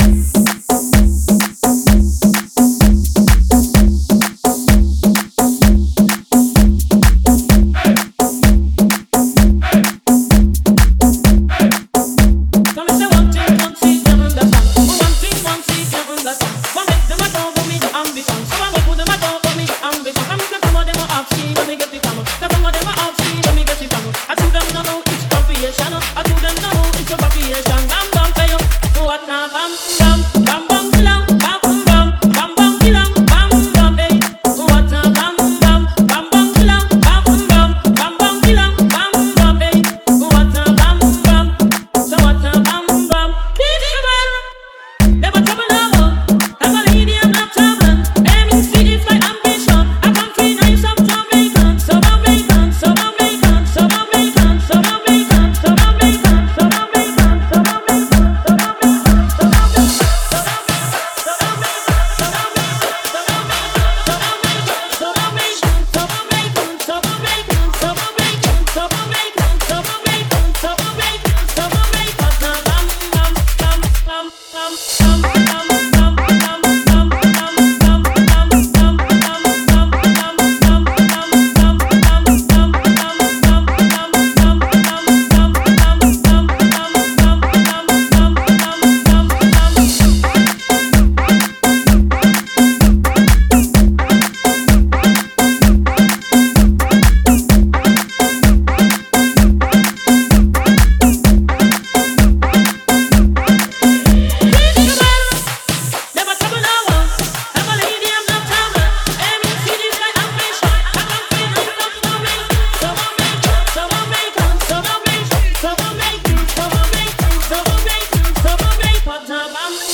Dance Hall Party
BPM 128